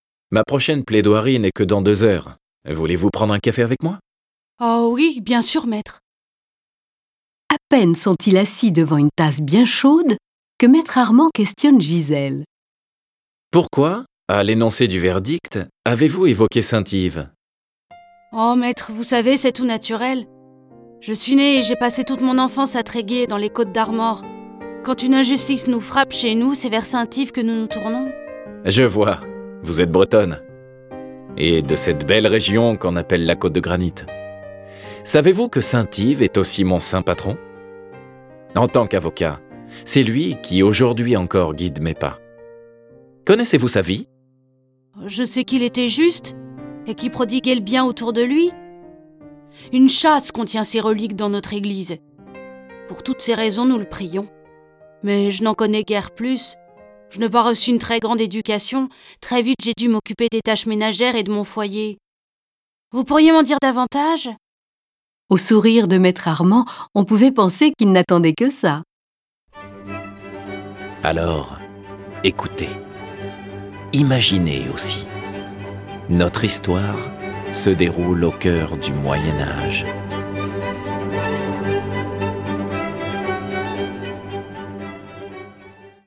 2011-10-01 Au milieu du XIIIe siècle, Yves Hélory de Kermartin a consacré sa vie à la justice et aux pauvres. Revivez les grand moments de la vie de ce saint patron des avocats et de la Bretagne : enfant à Tréguier, étudiant et professeur à Paris puis prêtre dans la région de son enfance. Cette version sonore de la vie d'Yves est animée par dix voix et accompagnée de près de quarante morceaux de musique classique.